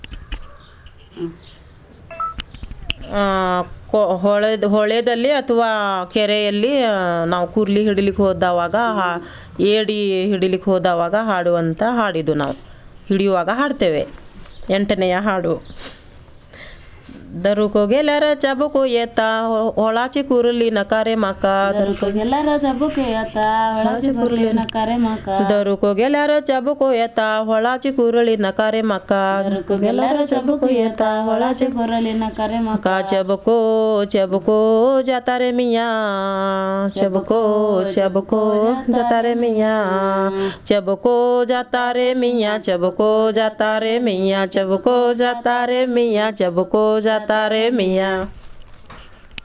Performance of traditional folk song